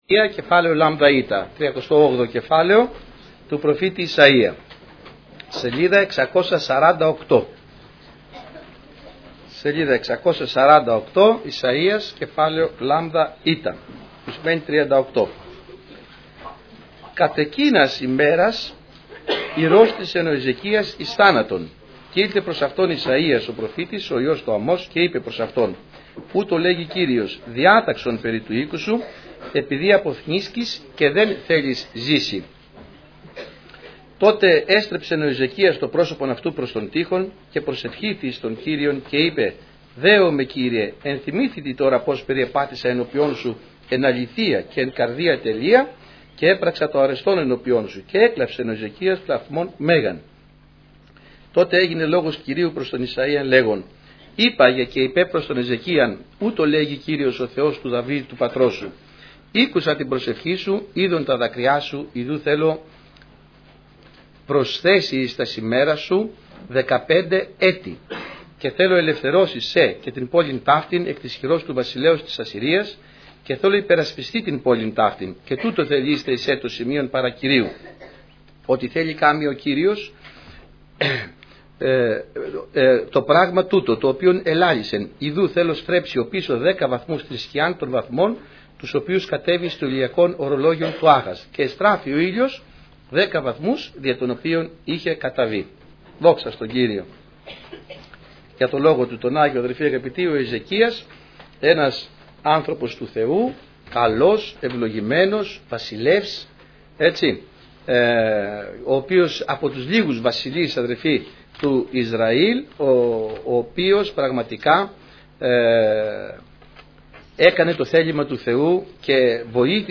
Στο Αρχείο Κηρυγμάτων θα βρείτε τα τελευταία Κηρύγματα, Μαθήματα , Μηνύματα Ευαγγελίου που έγιναν στην Ελευθέρα Αποστολική Εκκλησία Πεντηκοστής Αγίας Παρασκευής